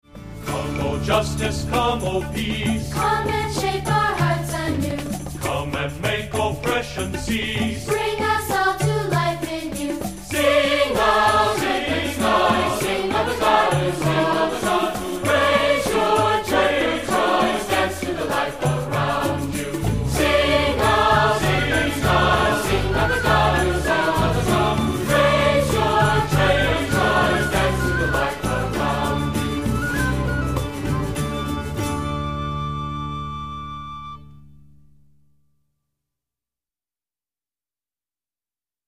Accompaniment:      Keyboard
Music Category:      Christian